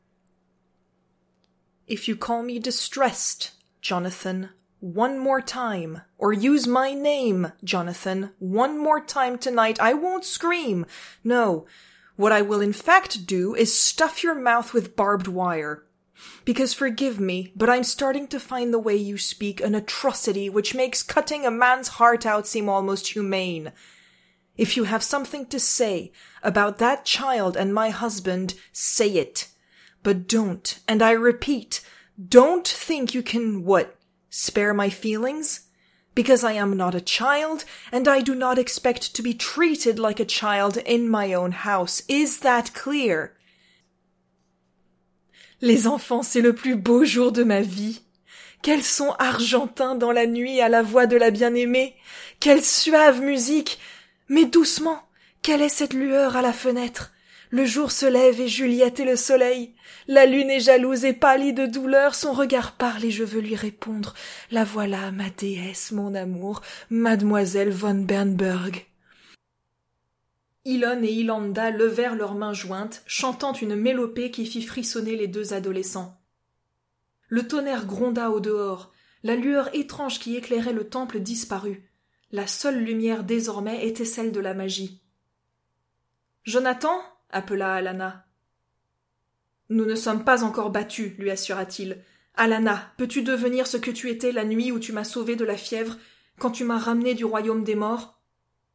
Démo voix Monologues EN, FR, audiobook FR
21 - 48 ans - Contralto